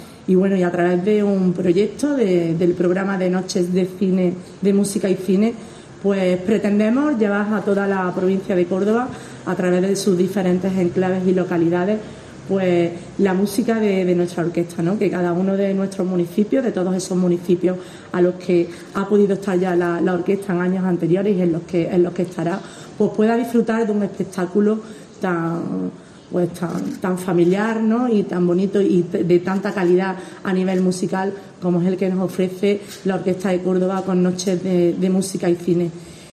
Escucha a la delegada de Cultura en la institución provincial, Salud Navajas